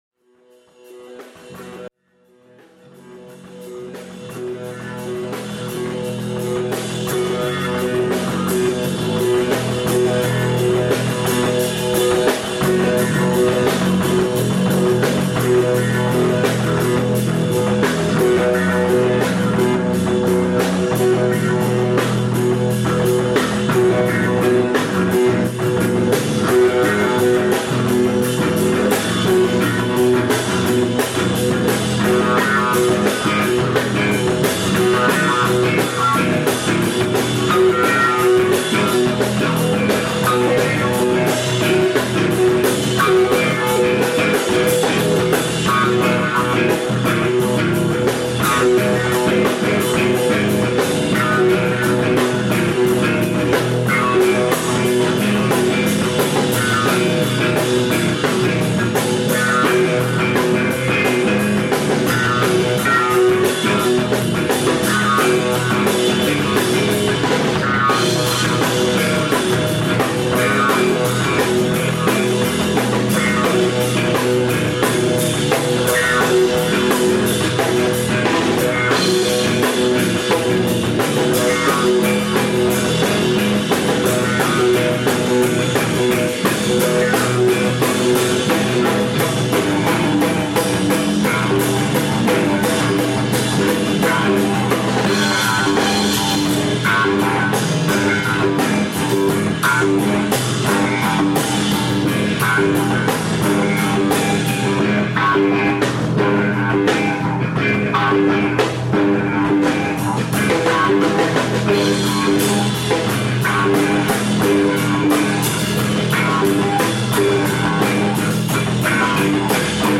Raw, unreleased practice jam.